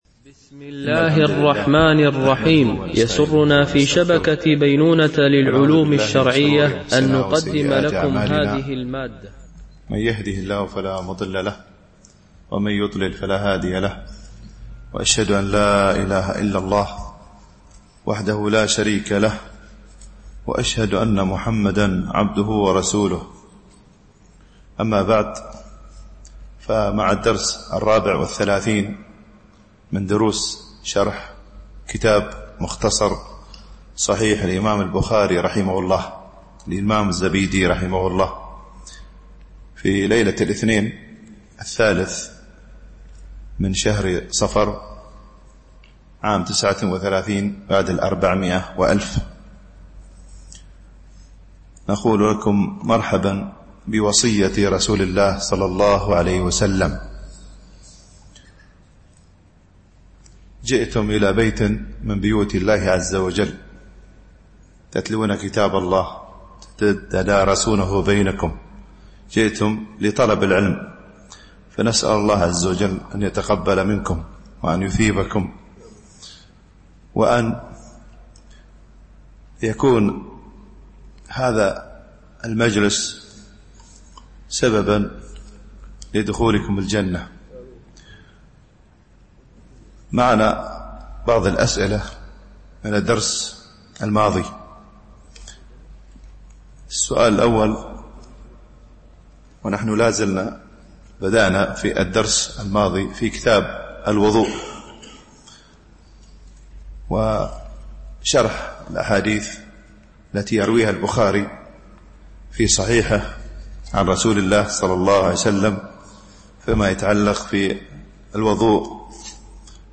شرح مختصر صحيح البخاري ـ الدرس 34 ( الحديث 115- 122)